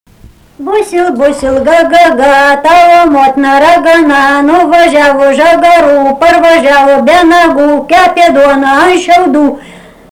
daina, vaikų